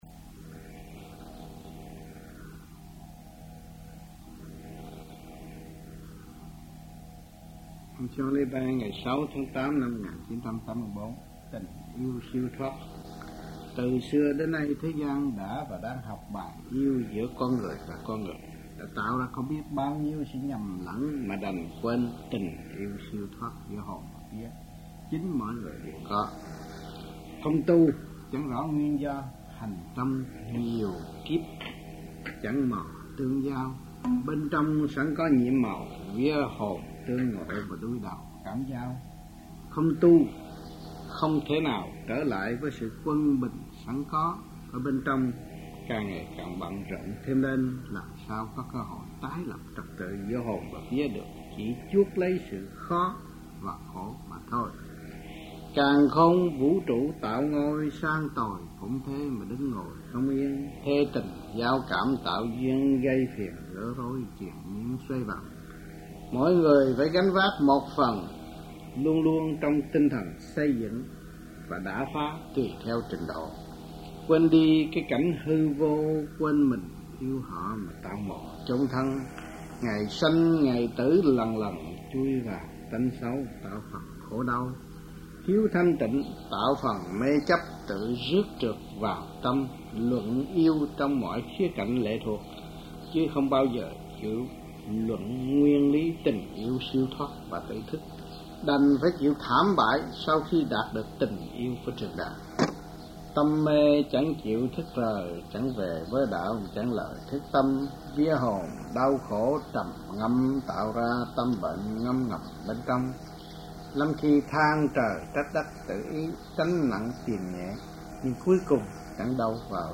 Địa danh : Amphion les Bains, France
Trong dịp : Sinh hoạt thiền đường